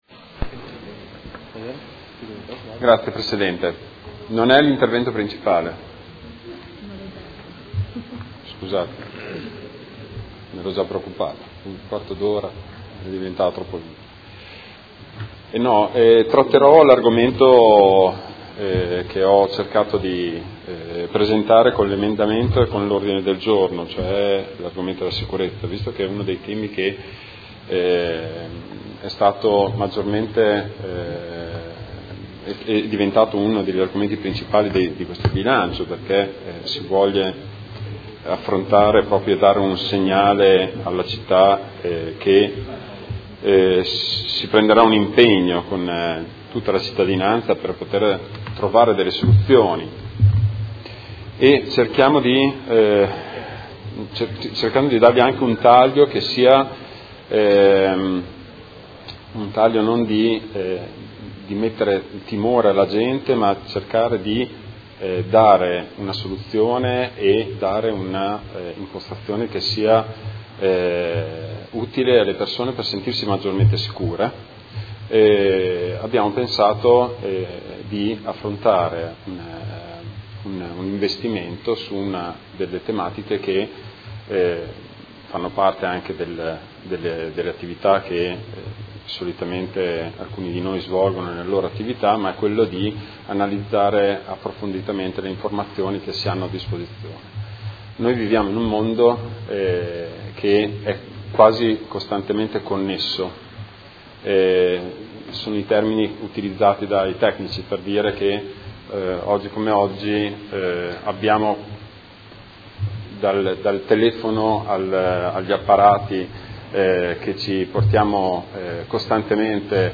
Marco Bortolotti — Sito Audio Consiglio Comunale
Seduta del 20/12/2018. Dibattito su delibera di bilancio, Ordini del Giorno, Mozioni ed emendamenti